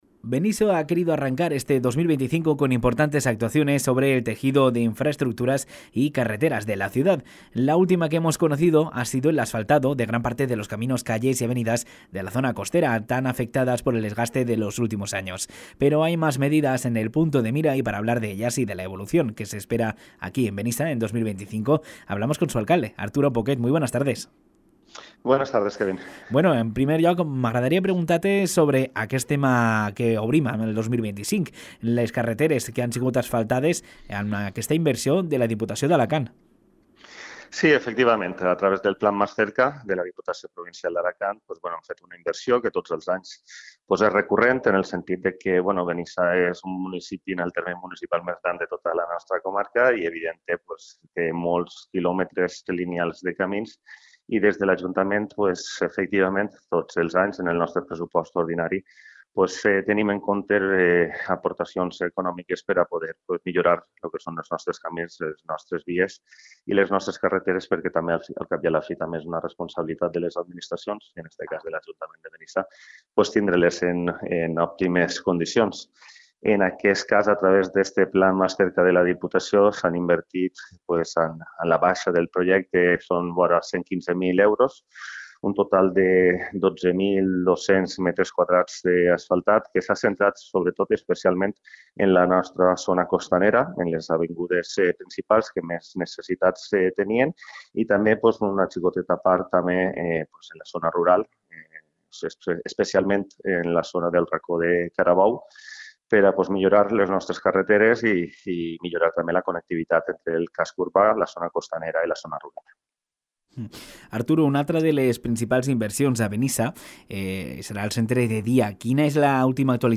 Però hi ha més mesures en el punt de mira, per a parlar d’elles i de l’evolució que s’espera de Benissa en 2025, hem pogut conversar amb el seu alcalde Arturo Poquet.
Entevista-Arturo-Poquet-asfaltado-carreteras.mp3